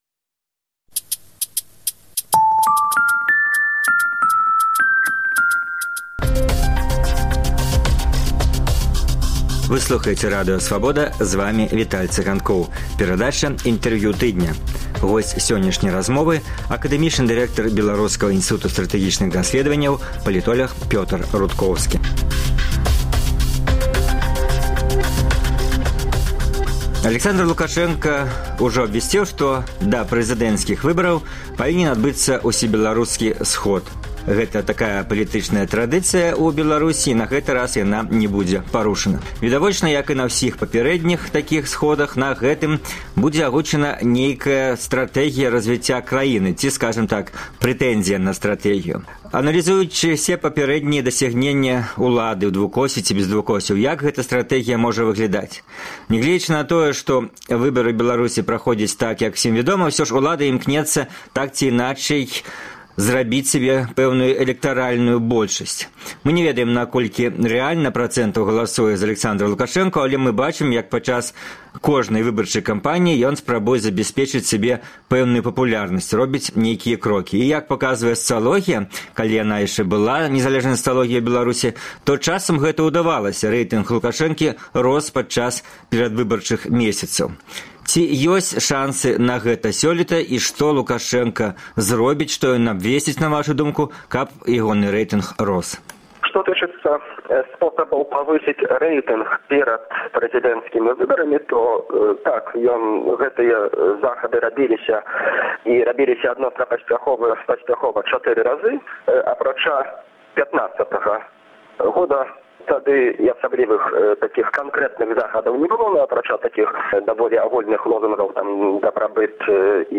Інтэрвію тыдня.